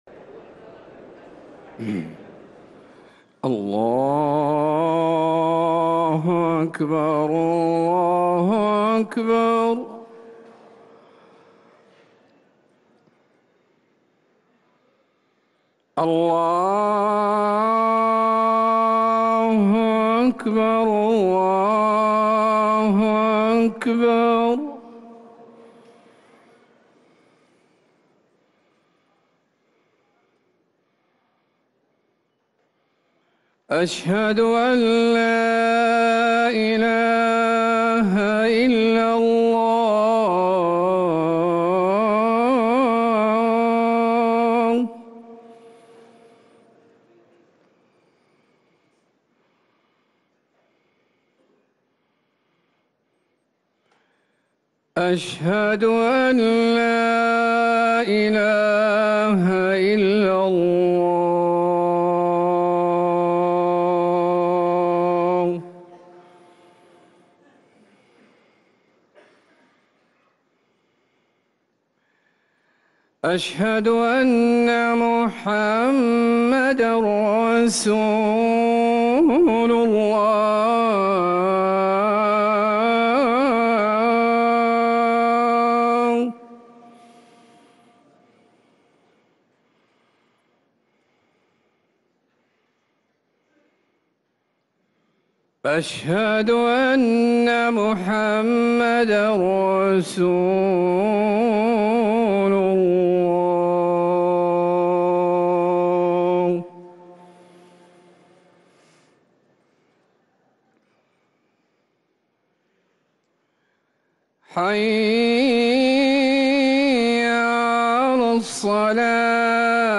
ركن الأذان 🕌